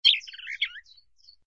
SZ_DG_bird_01.ogg